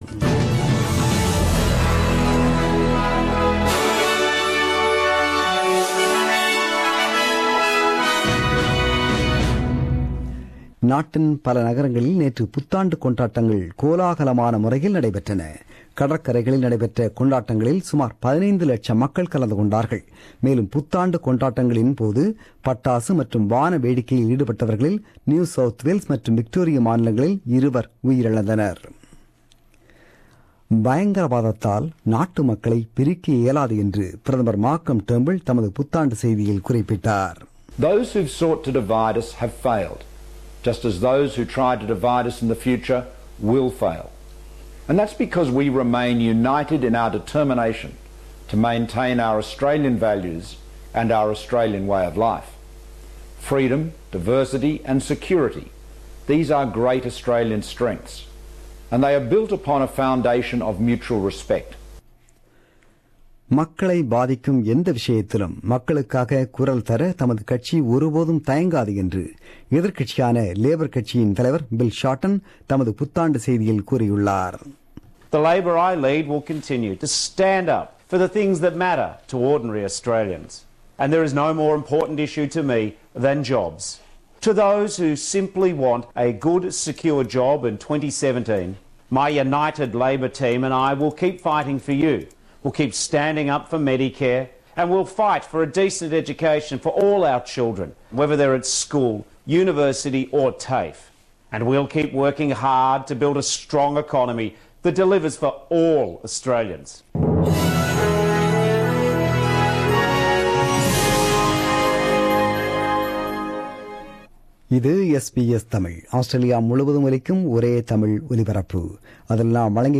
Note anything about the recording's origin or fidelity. The news bulletin broadcasted on 1 January 2017 at 8pm.